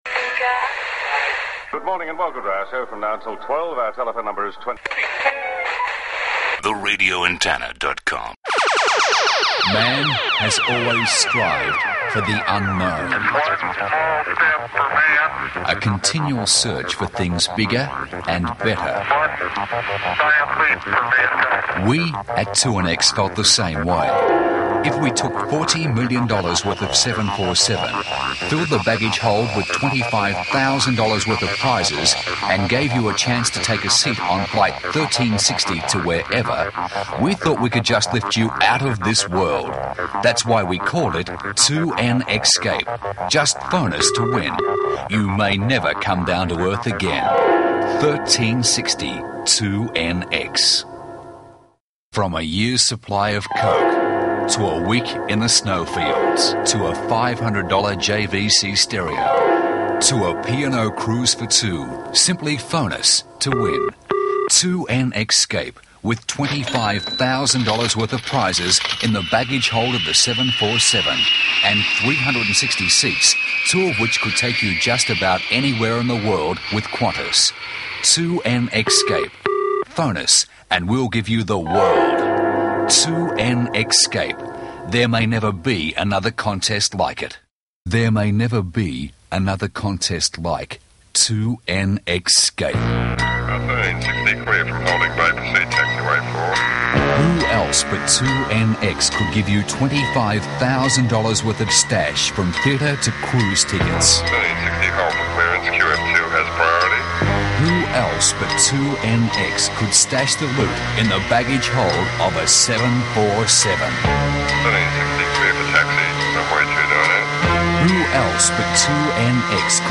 2NX-scape was one of three station promotions taking advantage of the X call sign.